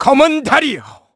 Riheet-Vox_Skill6_kr-02.wav